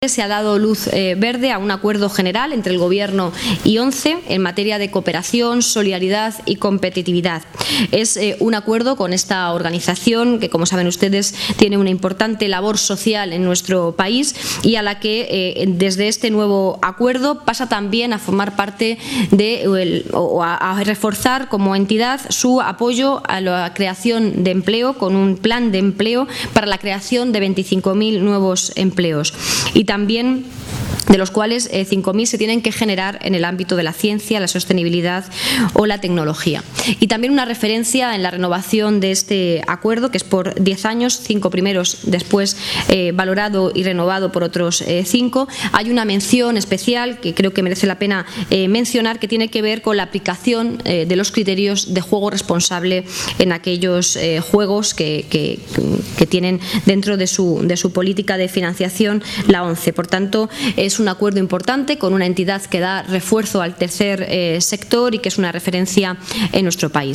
anunciado por la ministra portavoz, Isabel Rodríguez formato MP3 audio(1,28 MB).